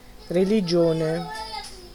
Ääntäminen
France: IPA: [ʁə.li.ʒjɔ̃]